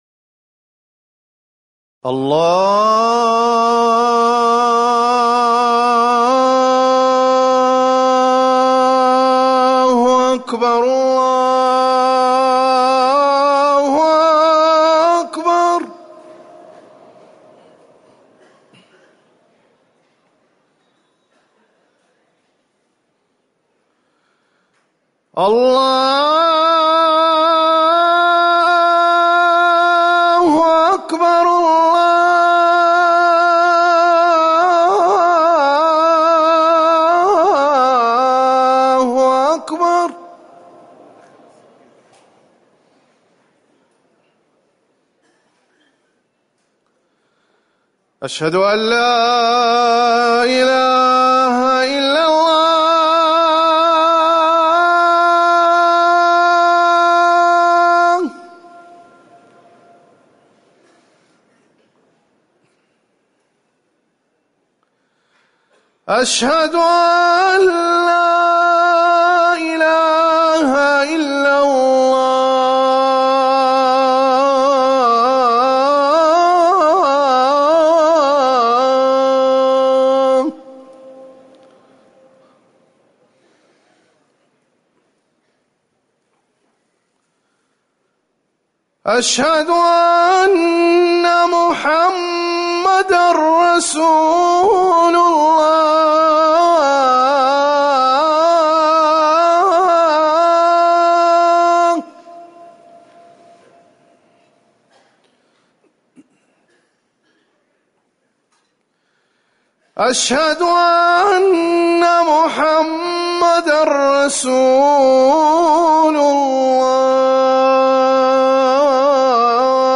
أذان العشاء
تاريخ النشر ٢٨ صفر ١٤٤١ هـ المكان: المسجد النبوي الشيخ